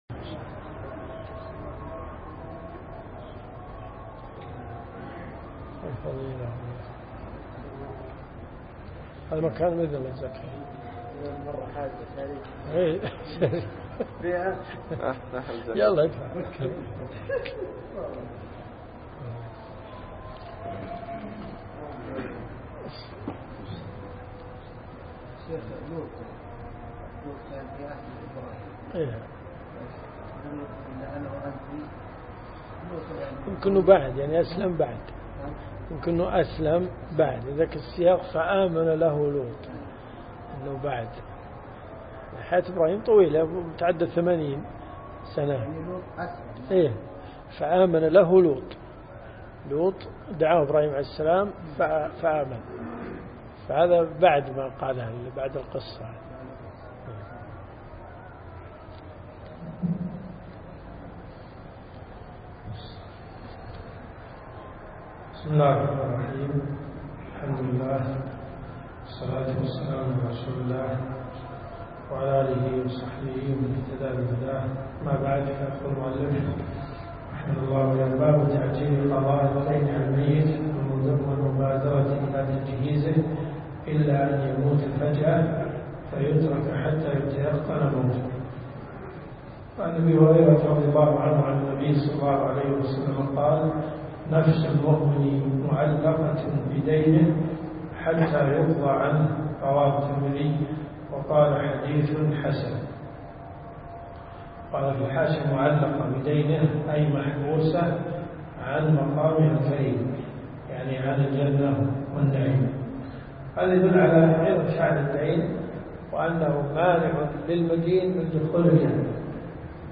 الرئيسية الكتب المسموعة [ قسم الحديث ] > مقتطفات من رياض الصالحين .